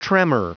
Prononciation du mot tremor en anglais (fichier audio)
Prononciation du mot : tremor